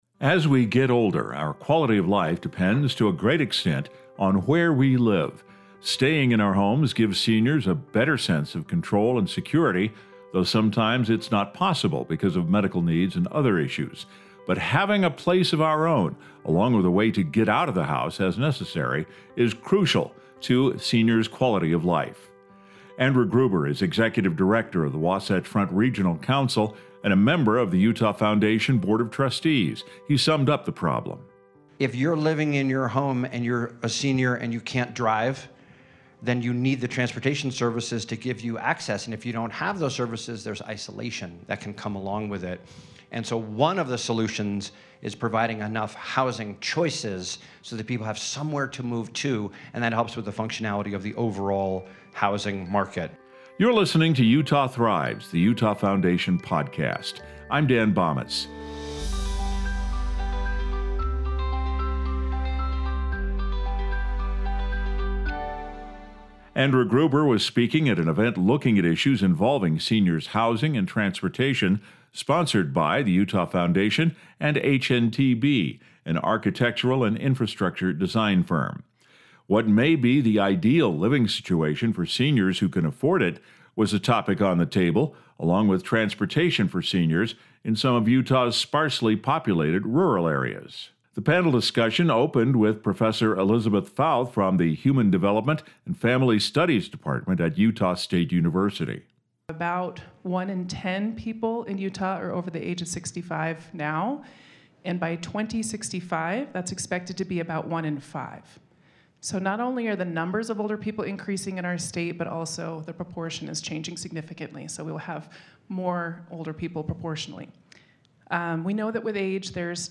As we age, all of us will need a safe place to live and a means of transportation to meet our basic needs and avoid the social isolation that can make life difficult for older Americans. Utah Foundation supporter HNTB recently sponsored a community conversation on the idea of “aging in place.” In this edition of Utah Thrives, we explore ways to address issues both big and small that will help set the agenda for future Utah Foundation research.